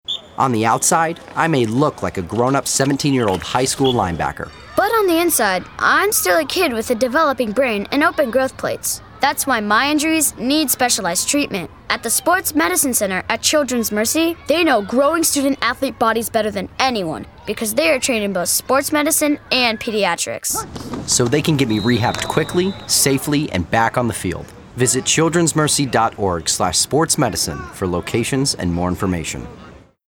:30 Radio